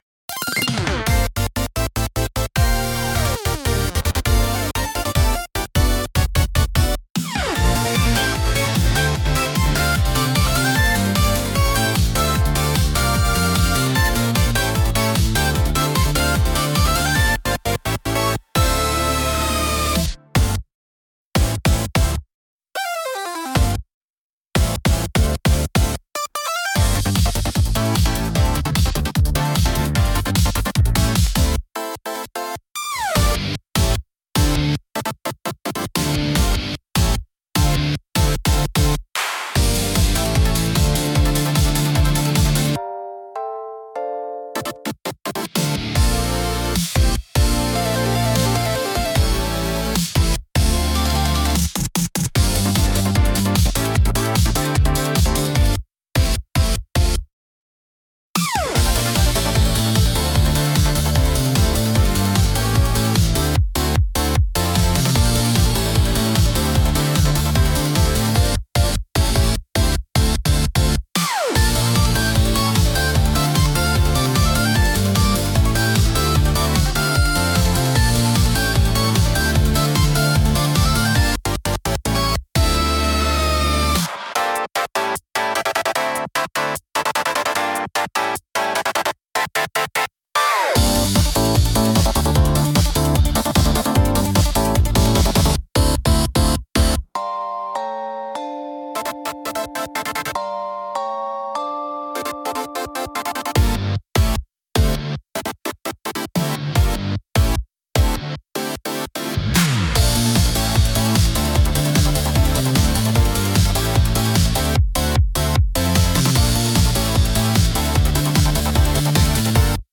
レースは、アップテンポで爽やかなシンセポップが主体の楽曲です。
明るく軽快なシンセサウンドとリズミカルなビートが疾走感を生み出し、スピード感あふれるエネルギッシュな空気を演出します。
聴く人の気分を高め、緊張と興奮を引き立てるダイナミックなジャンルです。